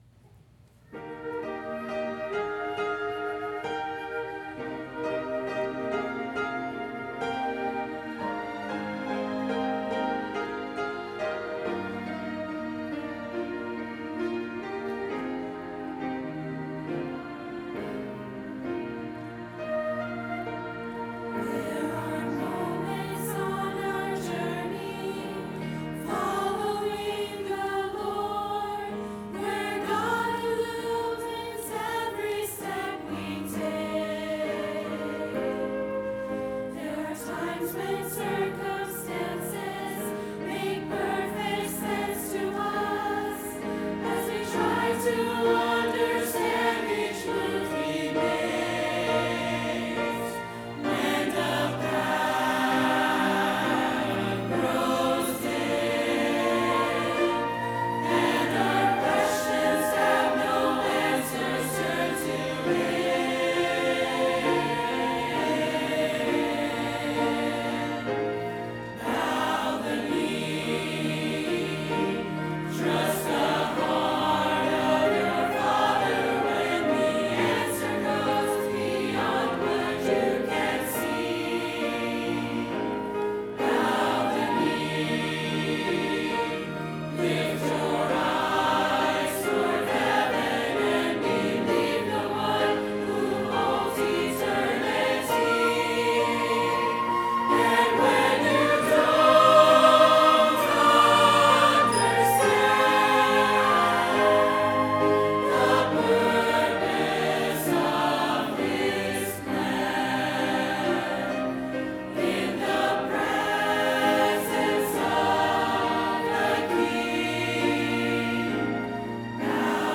by VBC Choir & Orchestra | Verity Baptist Church